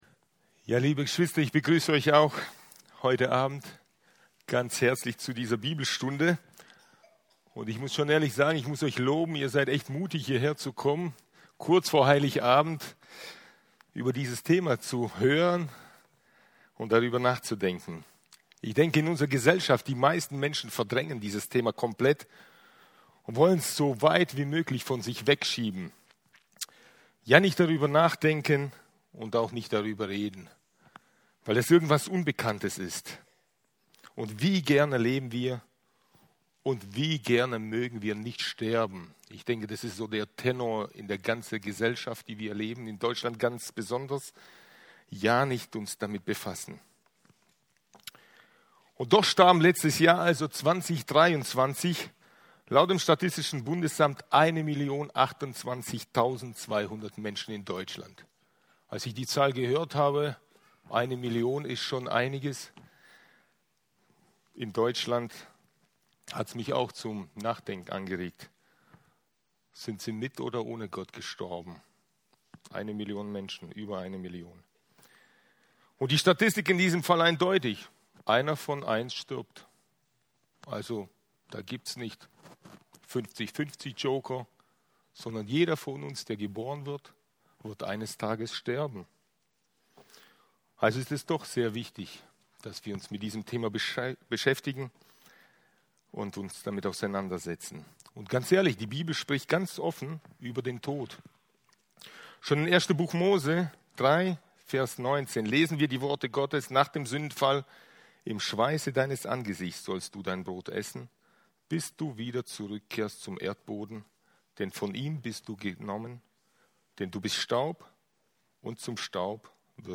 Hier findet ihr die Bibelstunden der Freikirchlichen Gemeinde Böbingen e.V.